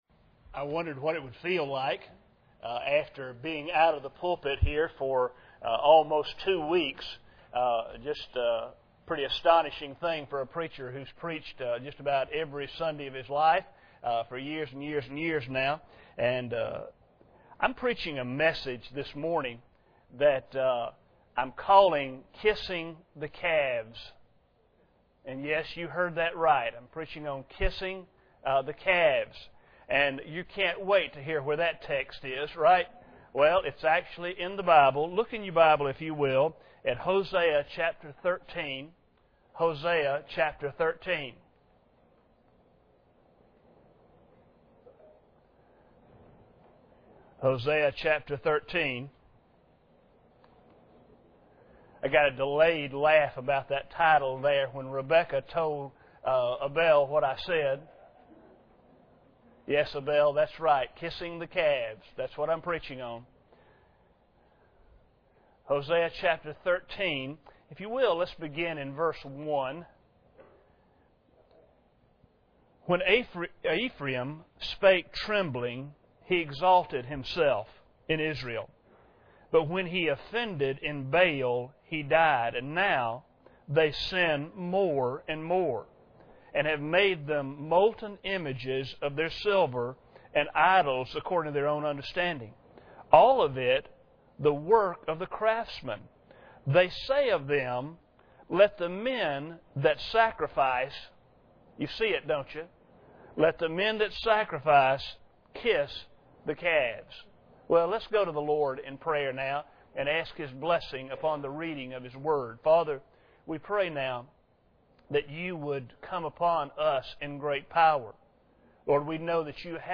Hosea 13:1-2 Service Type: Sunday Morning Bible Text